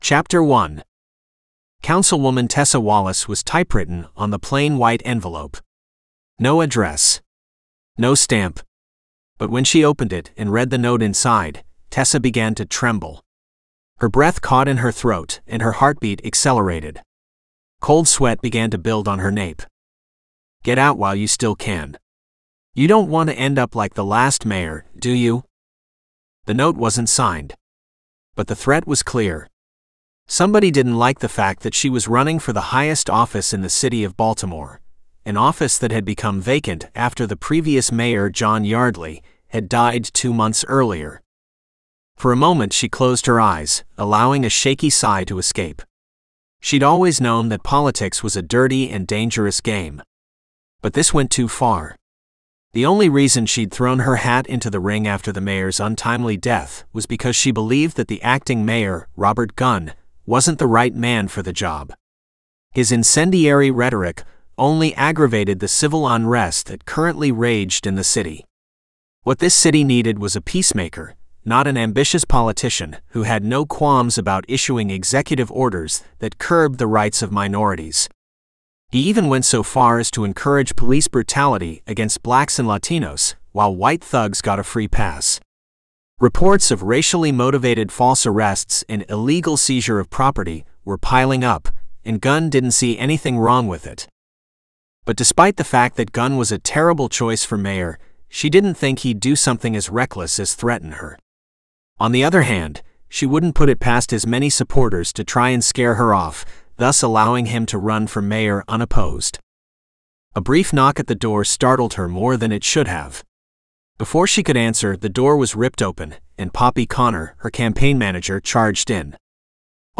Please listen to an excerpt of the AI-narrated audiobook!